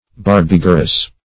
Search Result for " barbigerous" : The Collaborative International Dictionary of English v.0.48: Barbigerous \Bar*big"er*ous\, a. [L. barba a beard + -gerous.] Having a beard; bearded; hairy.